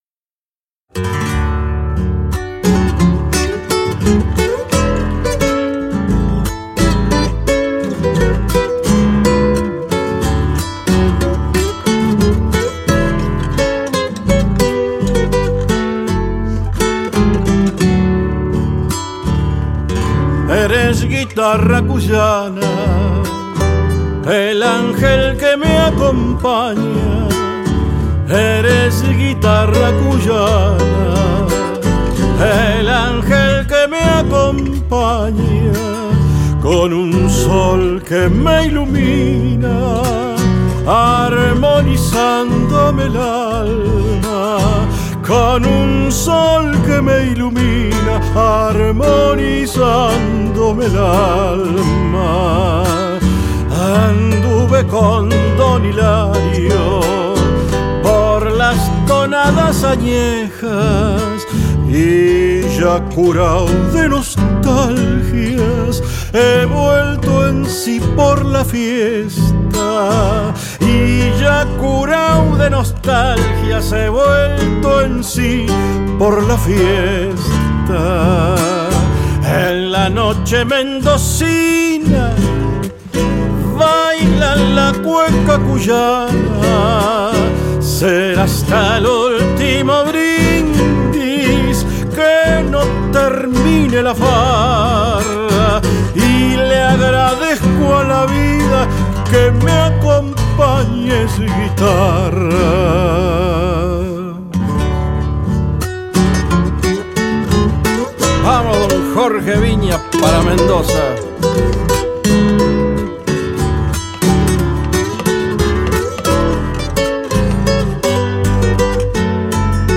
Cueca